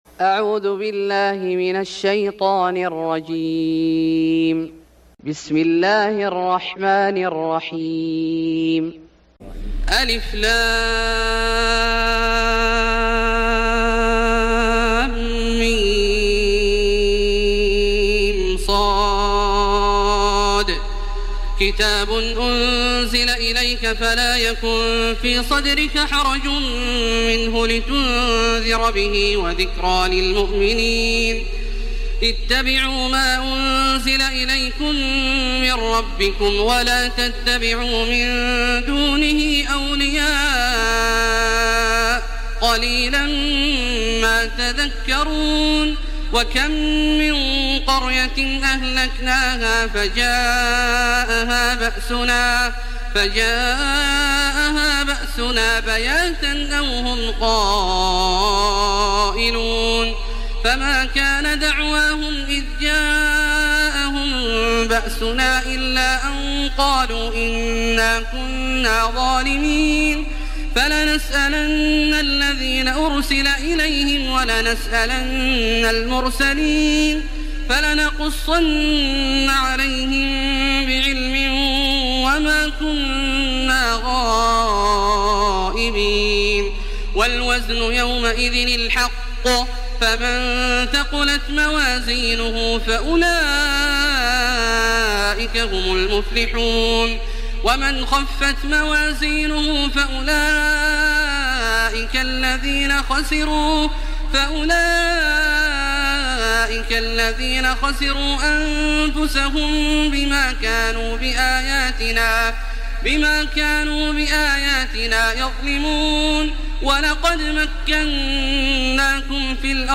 سورة الأعراف Surat Al-A'raf > مصحف الشيخ عبدالله الجهني من الحرم المكي > المصحف - تلاوات الحرمين